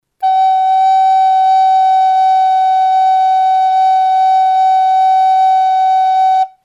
A nota FA# (Solb). Dixitación alternativa.